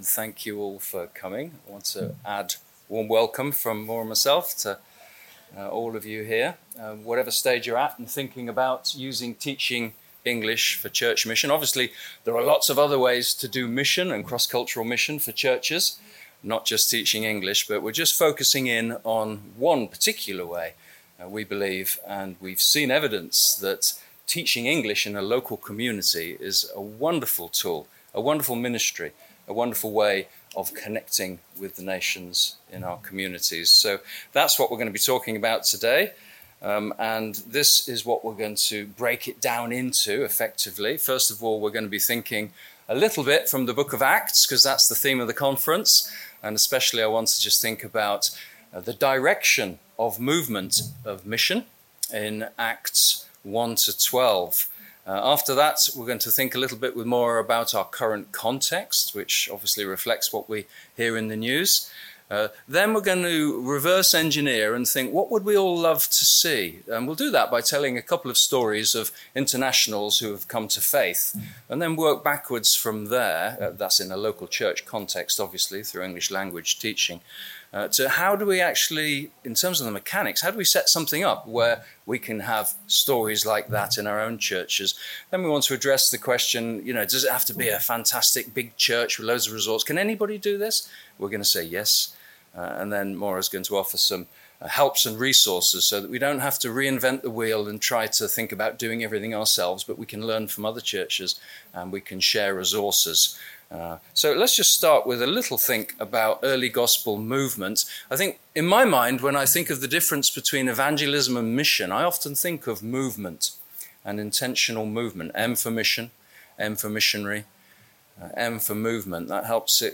Series: Leaders' Conference 2025